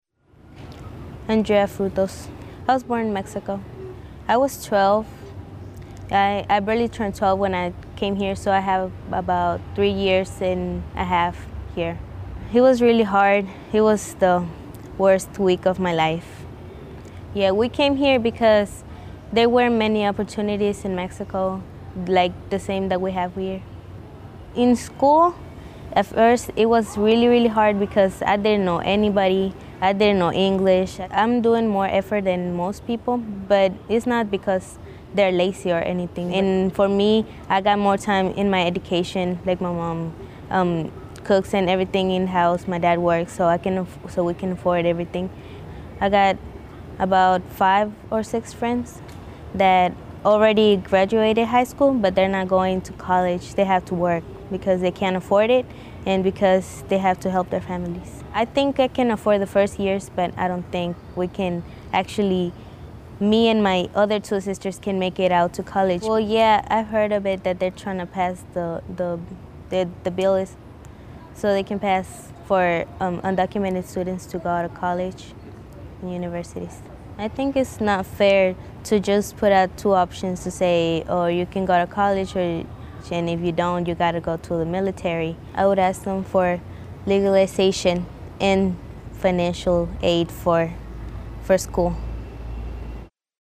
Listen to the voice of an undocumented student speaking out against the DREAM ACT